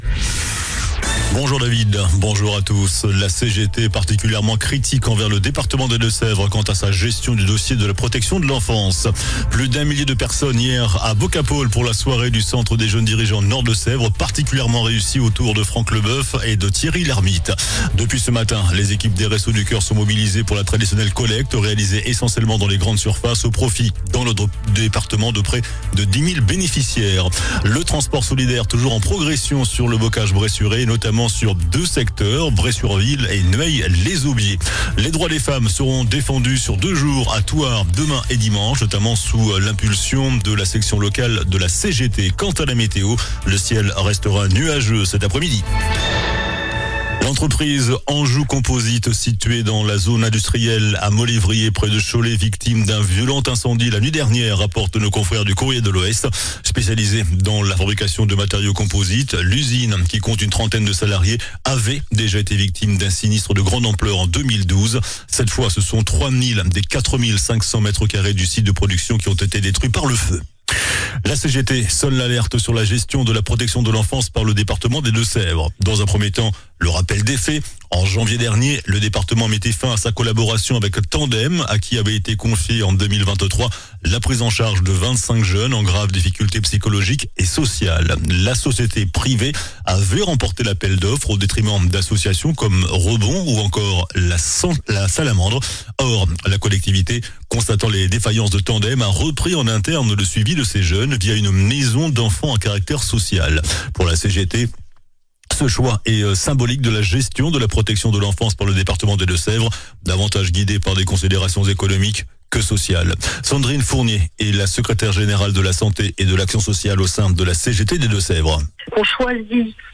JOURNAL DU VENDREDI 06 MARS ( MIDI )